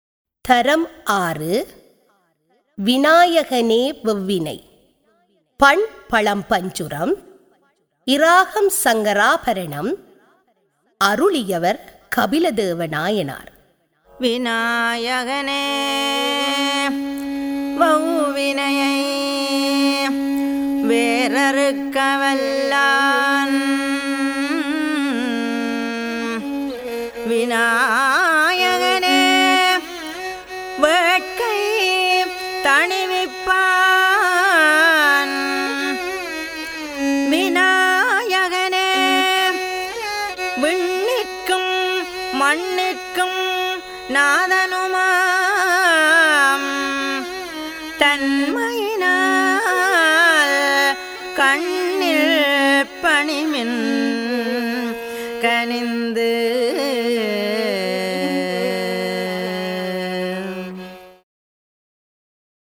தரம் 6 இல் கல்வி பயிலும் சைவநெறிப் பாடத்தை கற்கும் மணவர்களின் நன்மை கருதி அவர்கள் தேவாரங்களை இலகுவாக மனனம் செய்யும் நோக்கில் இசைவடிவாக்கம் செய்யப்பட்ட தேவாரப்பாடல்கள் இங்கே பதிவிடபட்டுள்ளன.